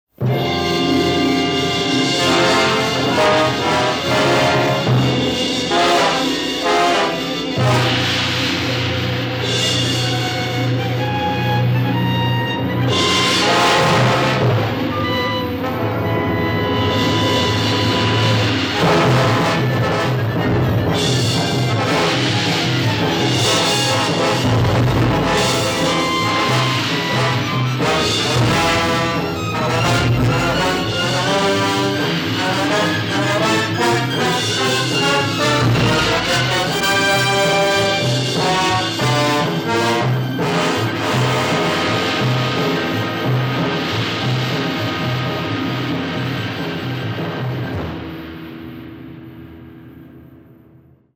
big and adventurous score
gives the film a more epic feel